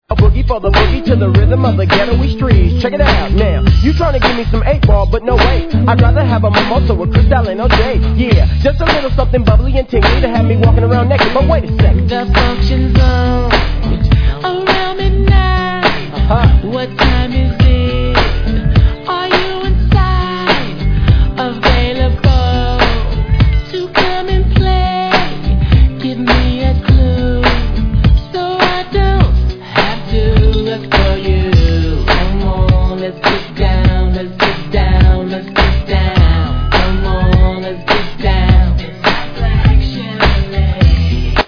Tag       NEW JACK R&B